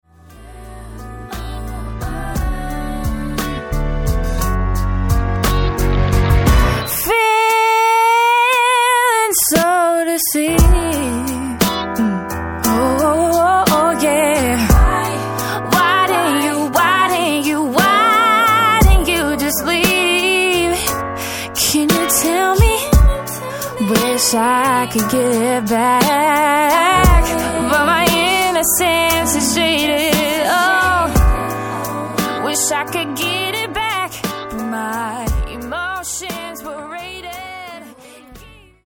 FREE SOUL